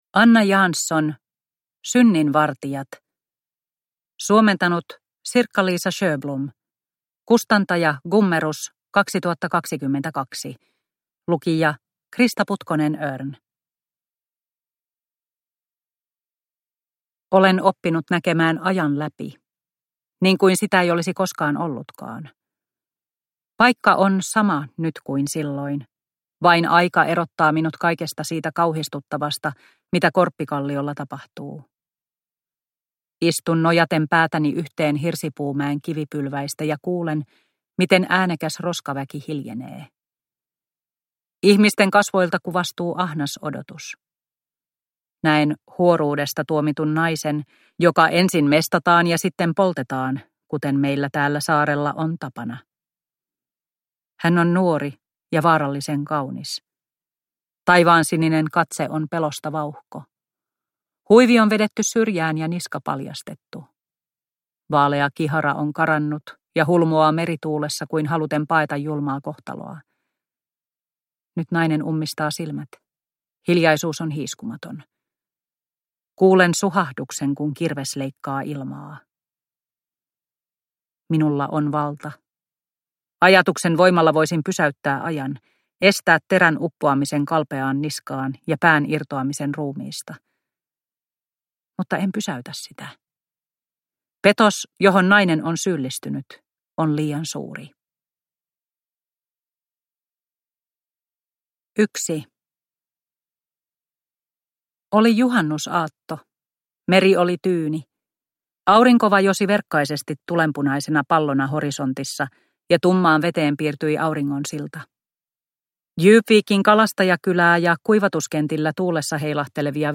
Synnin vartijat – Ljudbok – Laddas ner